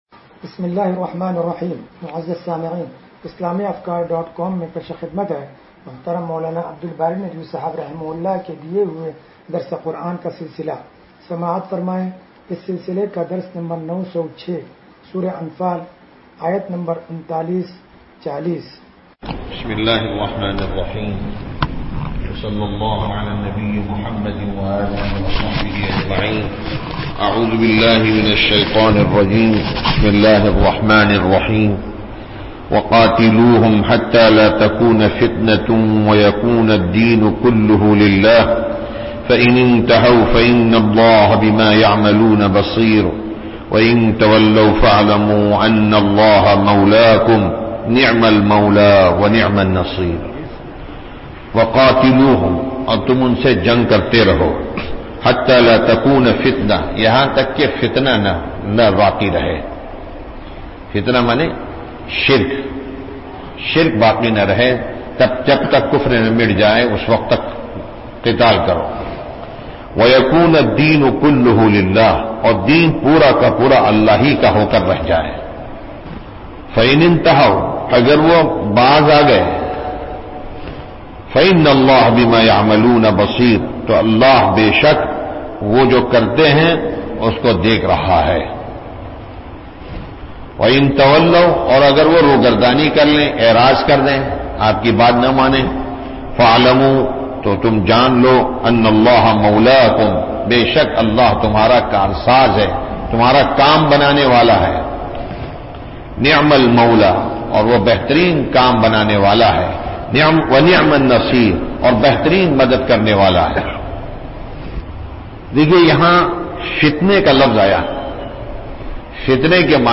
درس قرآن نمبر 0906